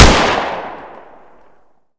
glock18-1.wav